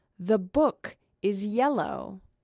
描述：字："黄"有女声
声道立体声